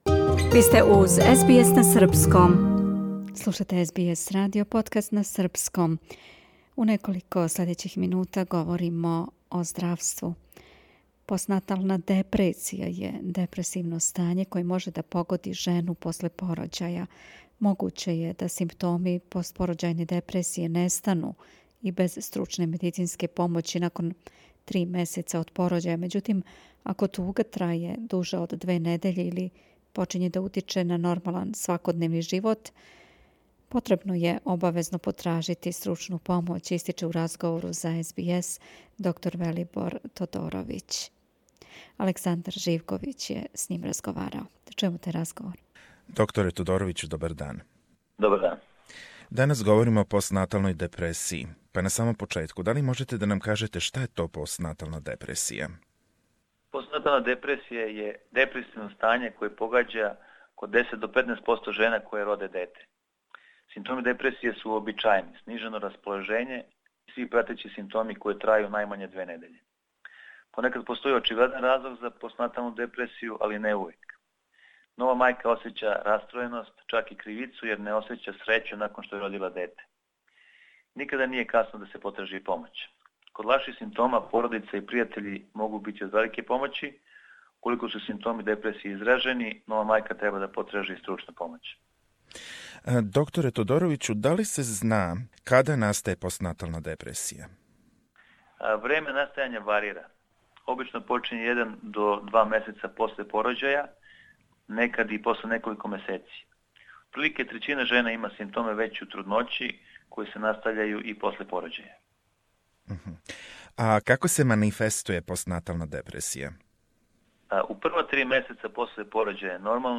Постнатална депресија је депресивно стање које може да погоди жену после порођаја. Могуће је да симптоми постпорођајне депресије нестану и без стручне медицинске помоћи након три месеца од порођаја. Међутим, ако туга траје дуже од две недеље или почиње да утиче на нормалан свакодневни живот, потребно је обавезно потражити стручну помоћ, истиче у разговору за СБС